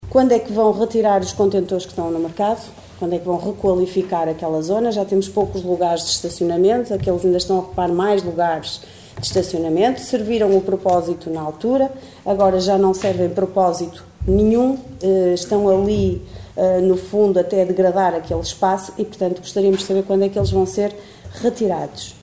A afirmação foi feita pela vereadora da Coligação O Concelho em Primeiro (OCP), Liliana Silva, na última reunião de Câmara.